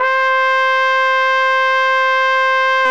Index of /90_sSampleCDs/Roland L-CD702/VOL-2/BRS_Tpt Cheese/BRS_Cheese Tpt